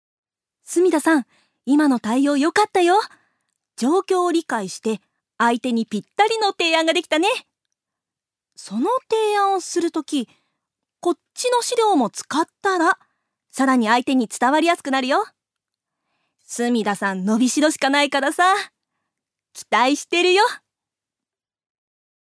女性タレント
音声サンプル
セリフ１